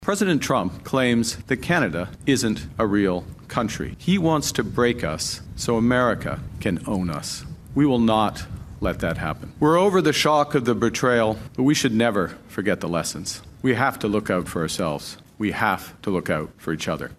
Speaking outside Rideau Hall after the meeting, Carney emphasized his focus on solutions rather than division and anger.